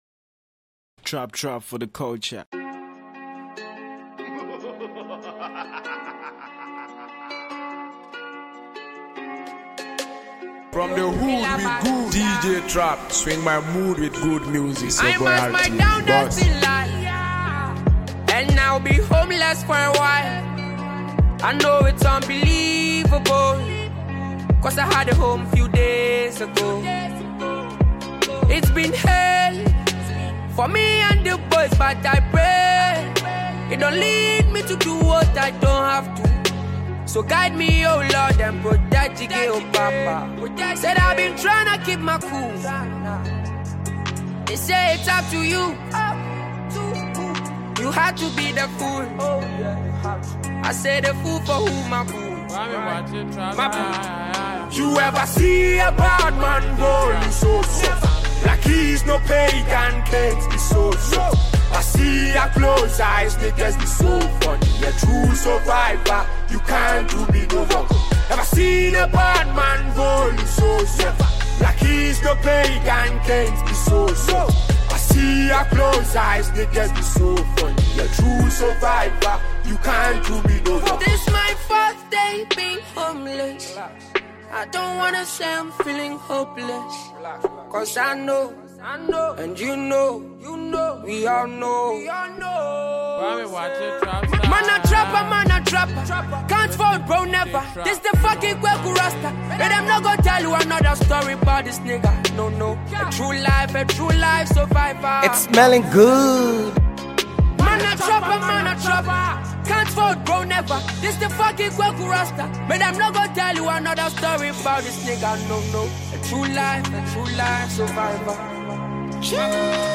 a raw, spiritual ride available as a free mp3 download.
this mix captures the hunger, pain, and power
dope and energetic tribute
DJ Mixtape
Ghana Afrobeat MP3